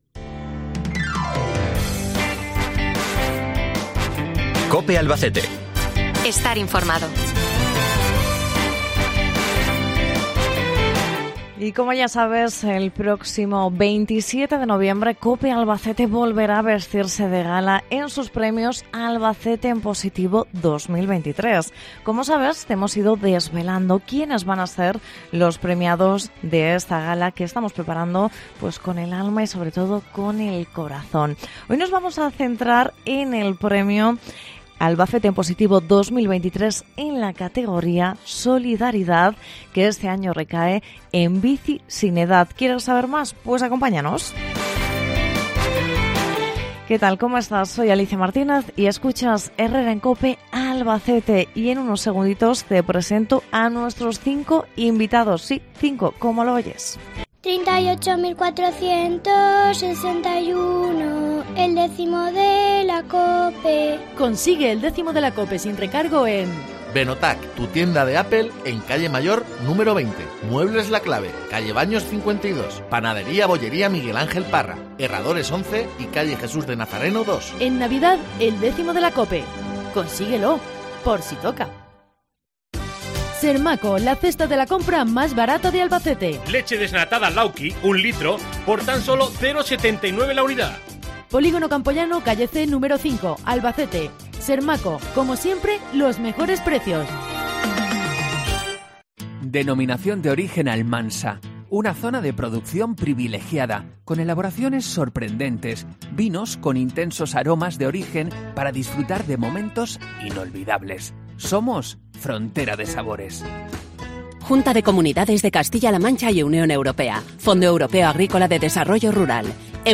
Entrevista En Bici Sin Edad, premio Solidaridad 'Albacete en Positivo 2023'